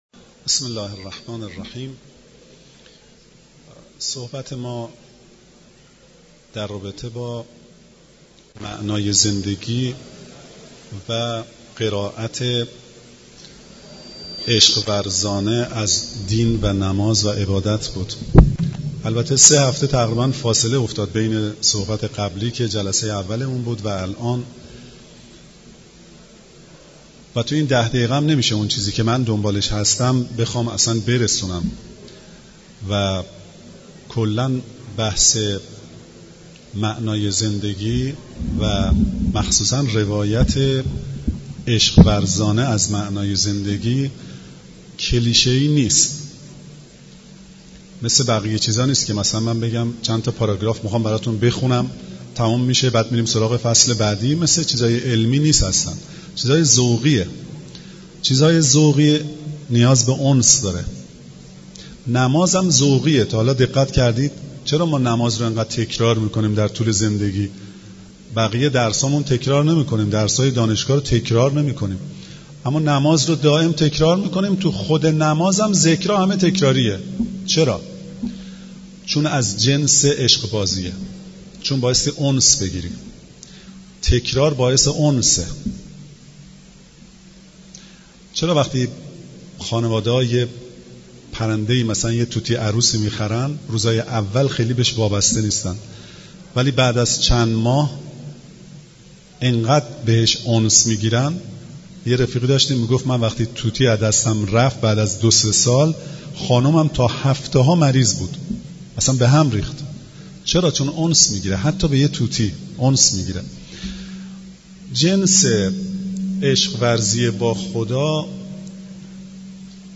سخنرانی
در مسجد دانشگاه کاشان